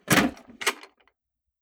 Ammo Crate Open 002.wav